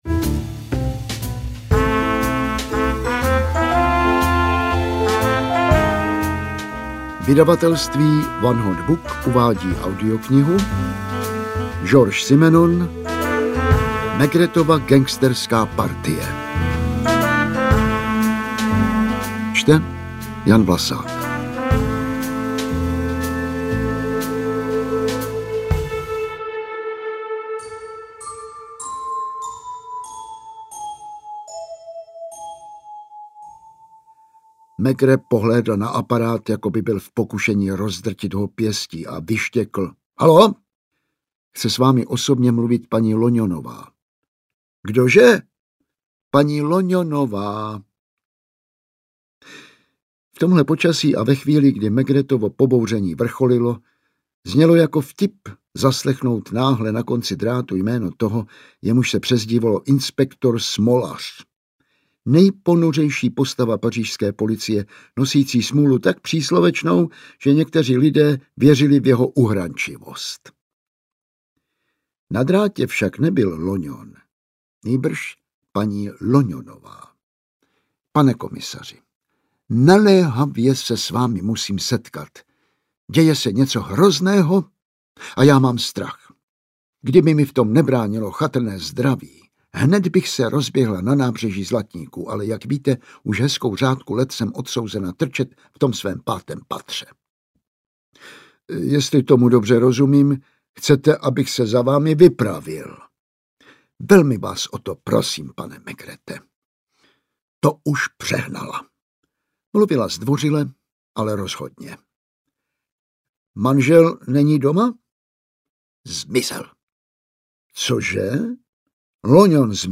Audio knihaMaigretova gangsterská partie
Ukázka z knihy
• InterpretJan Vlasák